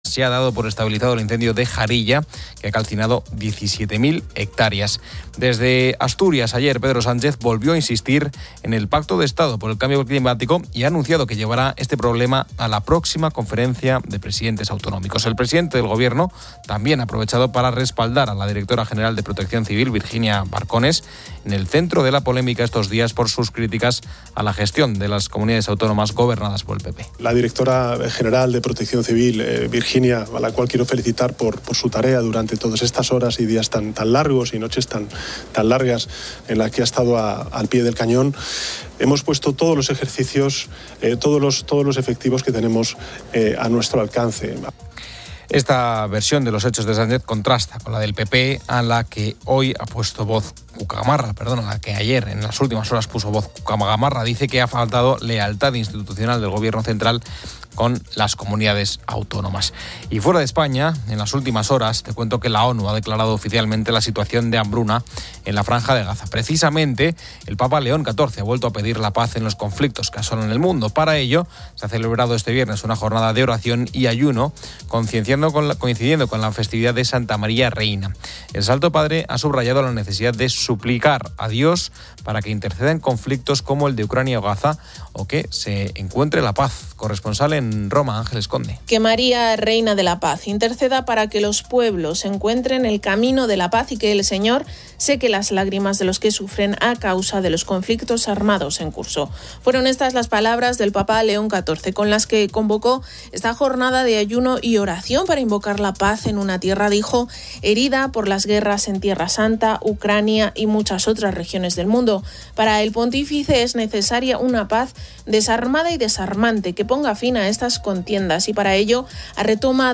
La ONU ha declarado hambruna en Gaza y el Papa Pío XIV hace un llamado global a la paz. En deportes, comienza la segunda jornada de LaLiga y se detallan partidos clave, además de eventos de motos y ciclismo. Una entrevista